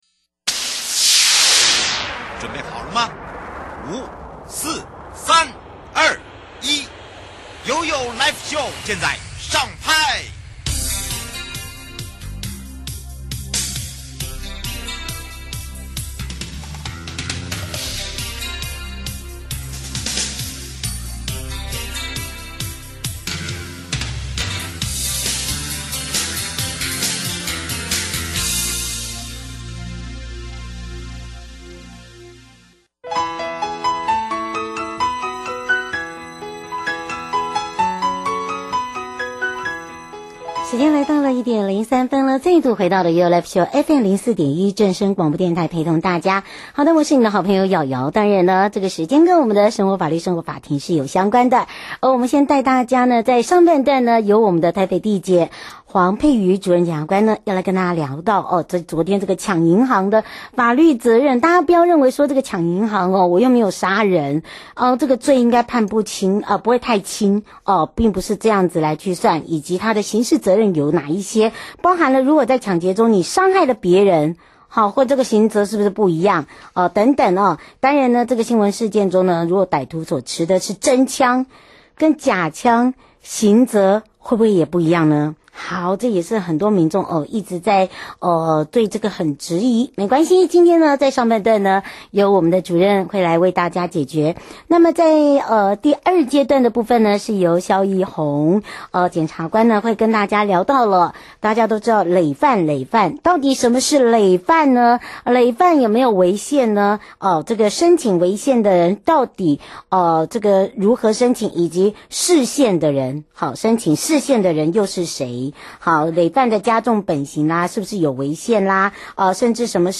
受訪者： 1. 台北地檢黃珮瑜主任檢察官 2. 台北地檢蕭奕弘檢察官 節目內容： 1.